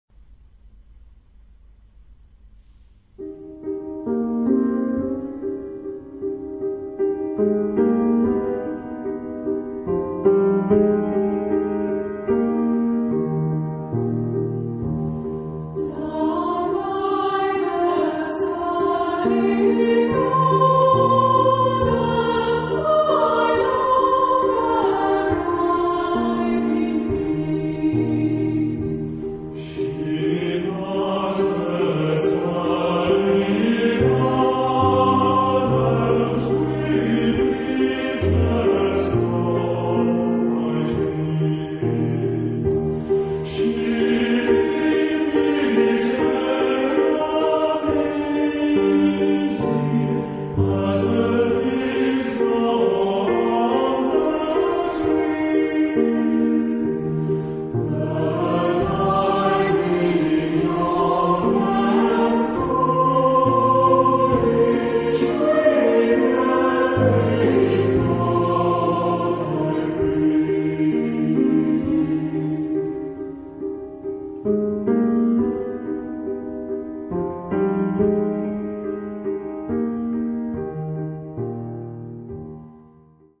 Genre-Style-Form: Folk music ; Secular
Mood of the piece: tender ; nostalgic
Type of Choir: unison  (1 unison voices )
Instruments: Piano (1)
Tonality: D flat major
Musicological Sources: Irish tune